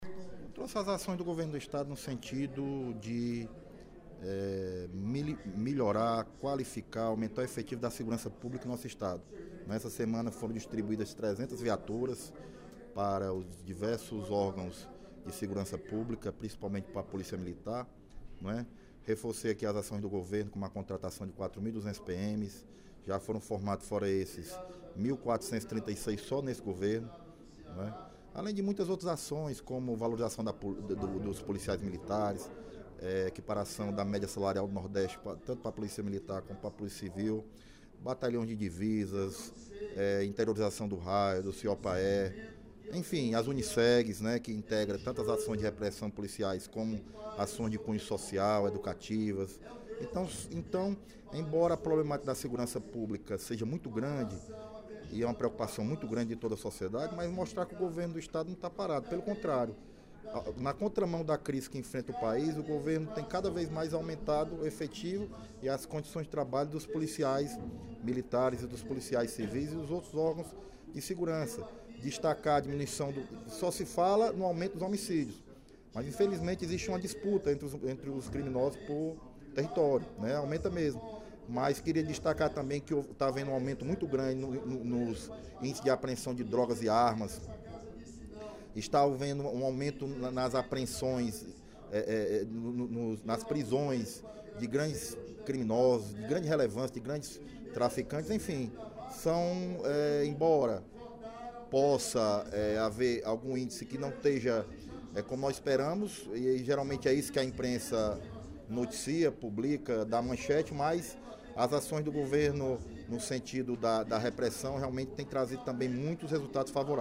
O deputado Leonardo Pinheiro (PP) ressaltou nesta quinta-feira (06/07), durante o primeiro expediente da sessão plenária, a entrega de cerca de 300 novas viaturas da Polícia Militar e Polícia Civil, reforçando o policiamento da Capital e Região Metropolitana.